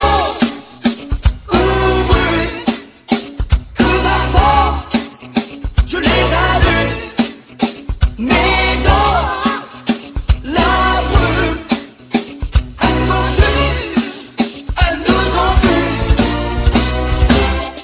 Voix rebelle, enrouée de blues